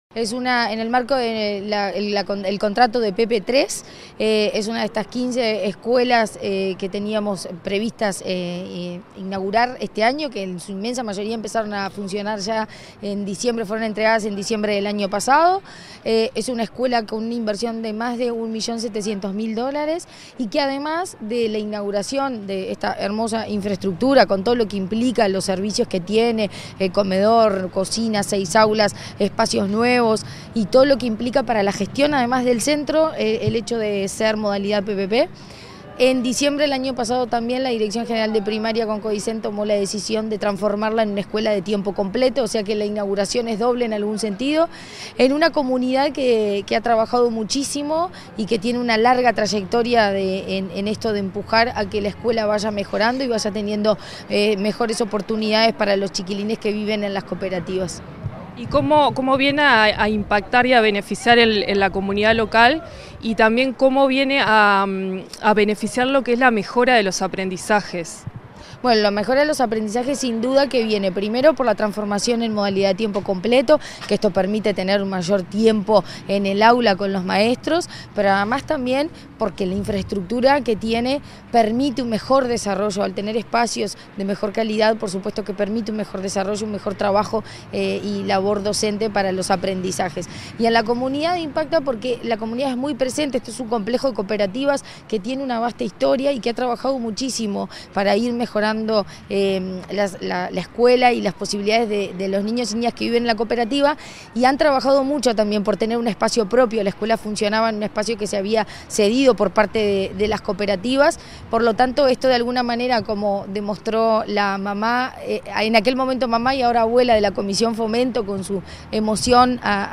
Entrevista a la presidenta de ANEP, Virginia Cáceres
Entrevista a la presidenta de ANEP, Virginia Cáceres 31/05/2024 Compartir Facebook X Copiar enlace WhatsApp LinkedIn Comunicación Presidencial dialogó con la presidenta del Consejo Directivo Central de la Administración Nacional de Educación Pública (ANEP), Virginia Cáceres, tras la inauguración del edificio de la escuela n.° 264 en el barrio 3 de Abril.
caceres entrevista.mp3